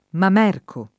[ mam $ rko ]